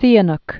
(sēə-nk), King Norodom 1922-2012.